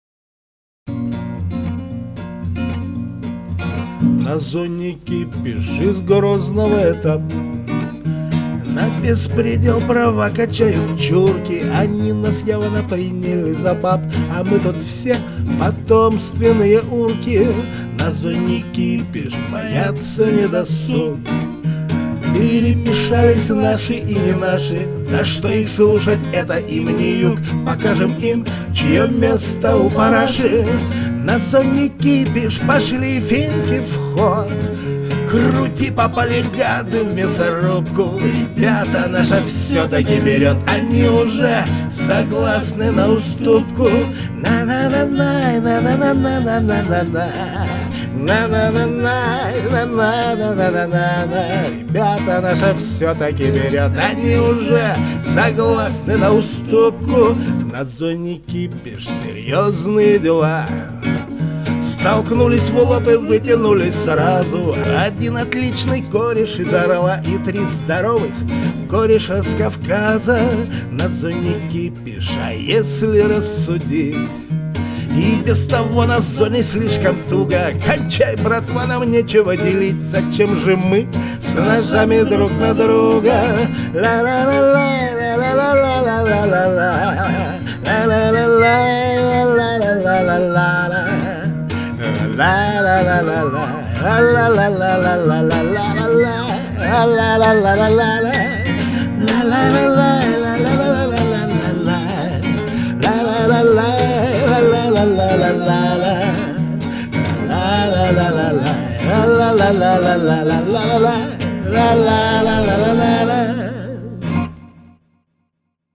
(акустика)